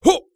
ZS发力1.wav 0:00.00 0:00.36 ZS发力1.wav WAV · 31 KB · 單聲道 (1ch) 下载文件 本站所有音效均采用 CC0 授权 ，可免费用于商业与个人项目，无需署名。
人声采集素材/男3战士型/ZS发力1.wav